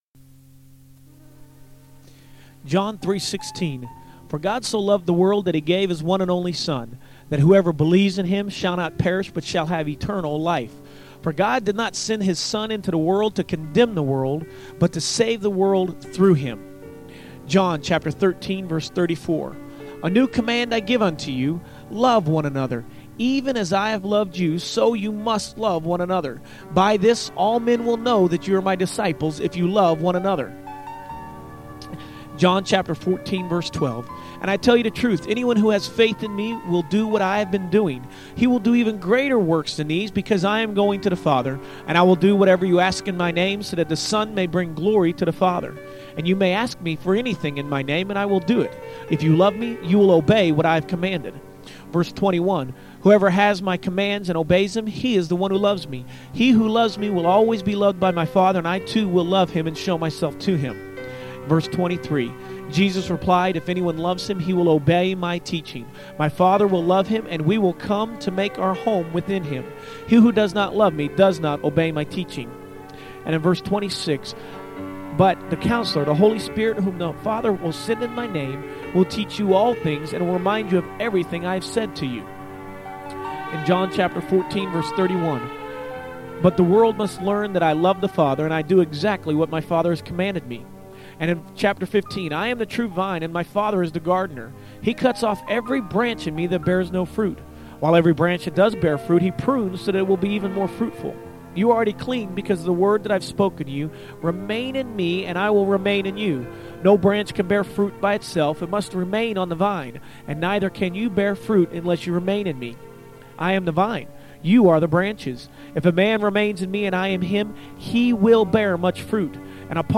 Love Scriptures with music